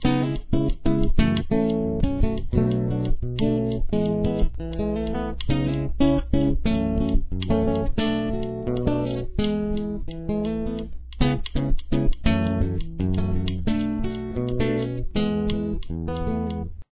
The acoustic is in standard tuning.
If you have trouble, check out the audio to hear the rests.